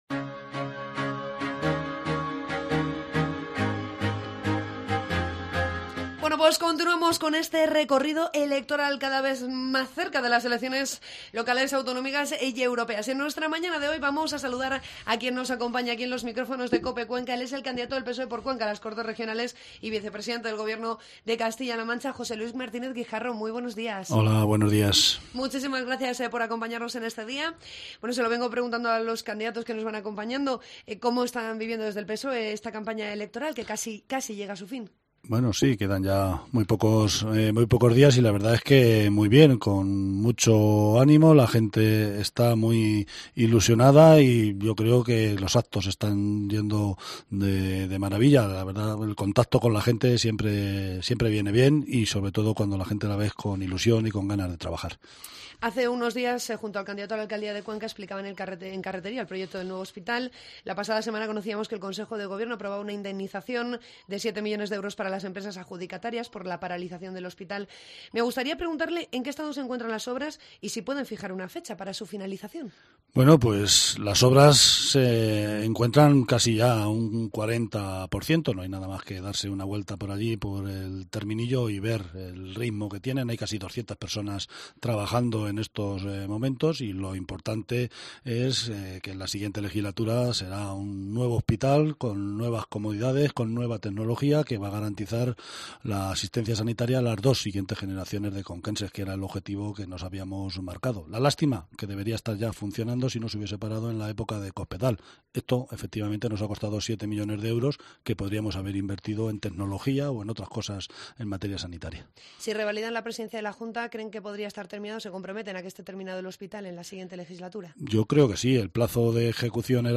Ya puedes escuchar la entrevista con José Luis Martínez Guijarro